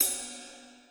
ride.wav